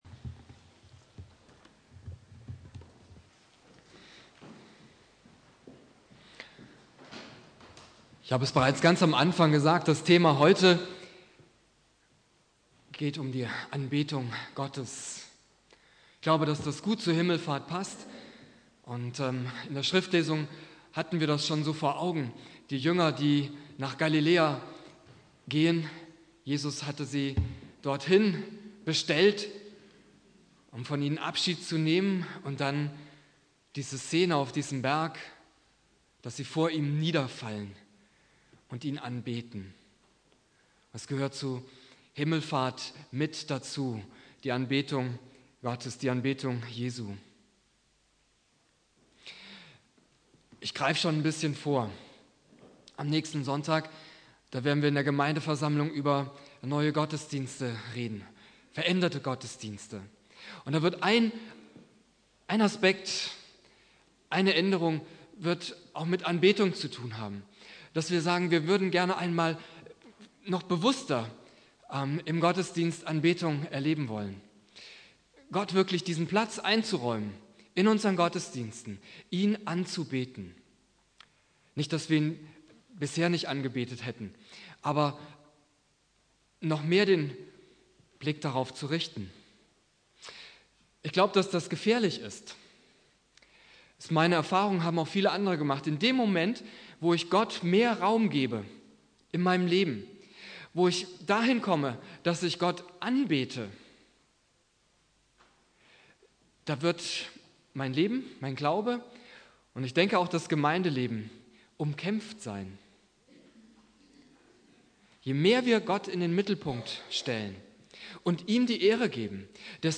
Predigt
Christi Himmelfahrt Prediger